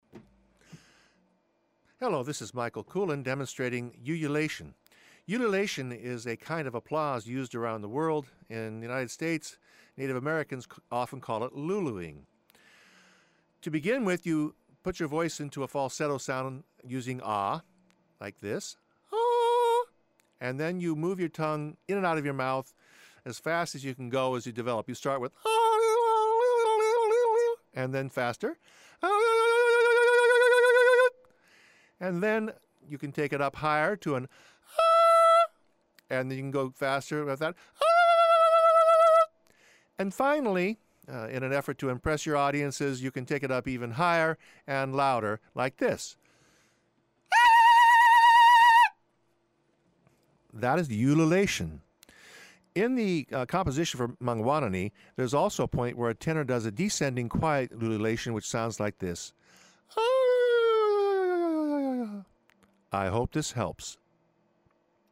How to Ululate–another way to applaud and participate as an audience member.
ululation.mp3